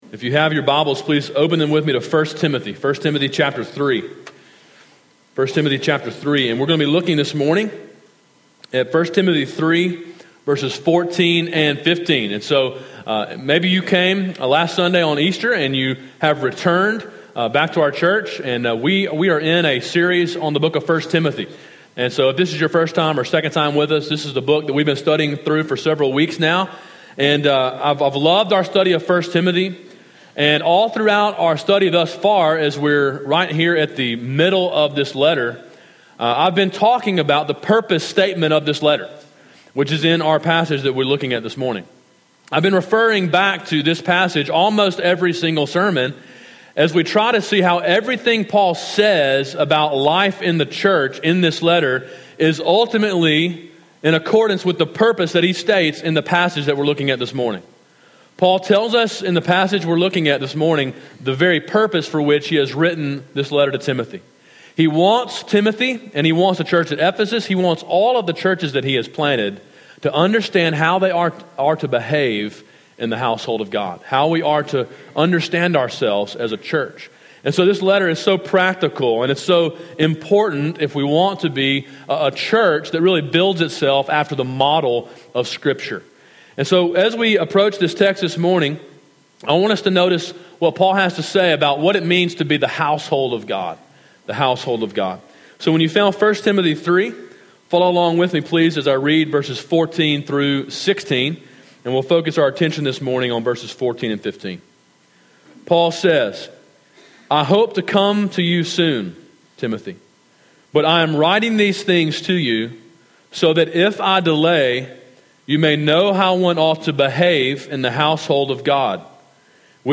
Sermon: “The Household of God” (1 Timothy 3:14-15)
A sermon in a series on the book of 1 Timothy.